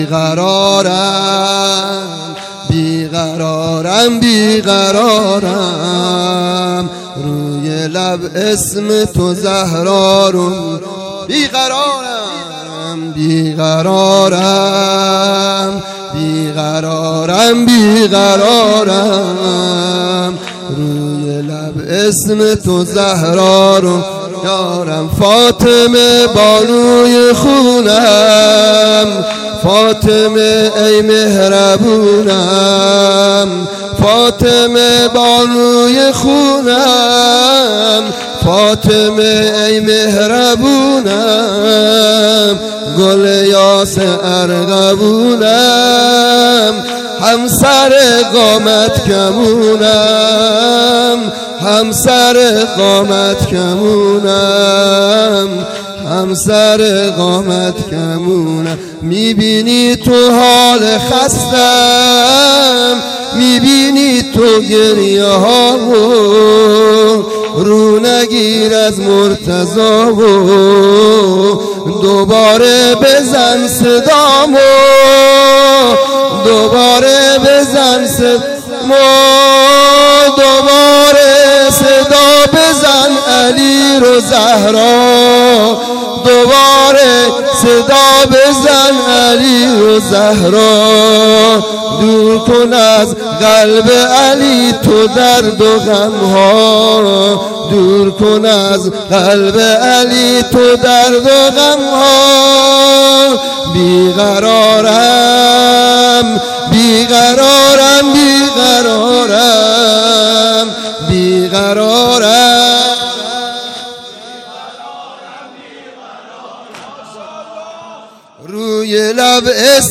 فاطمیه اول - سال 1397
مداحی ایام فاطمیه